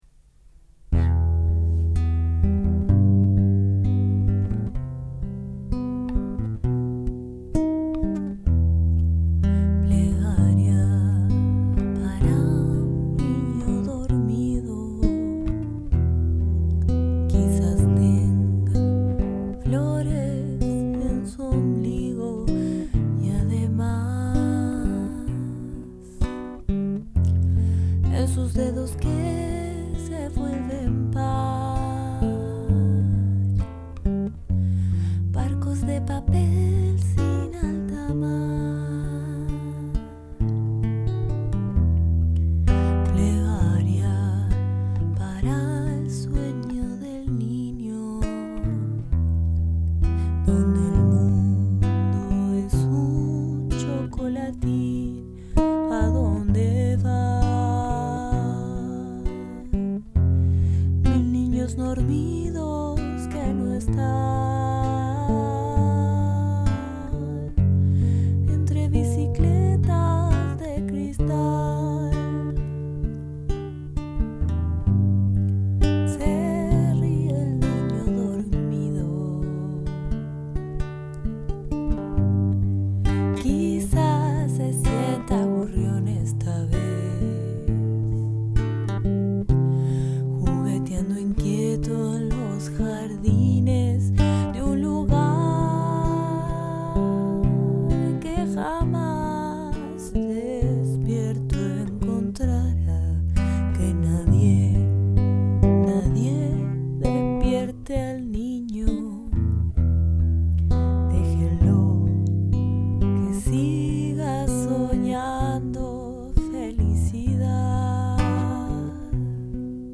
cover acustico
20 - 35 ans - Contralto